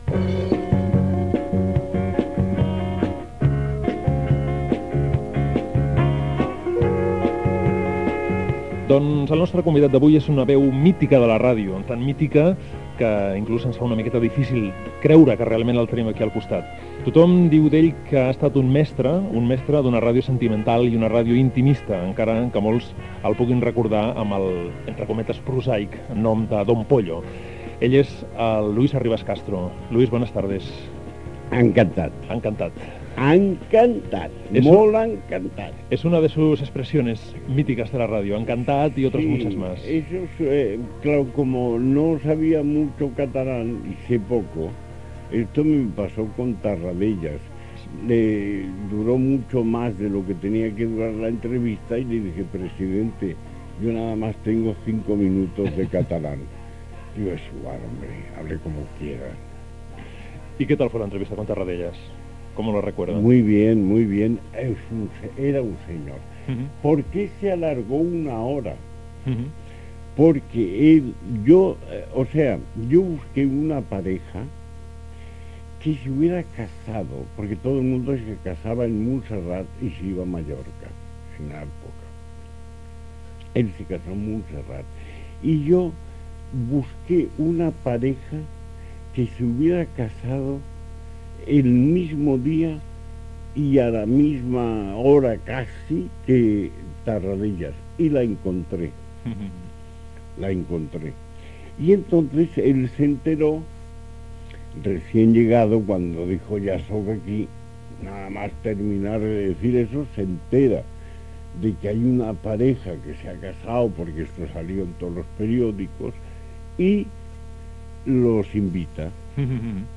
Entrevista
Entreteniment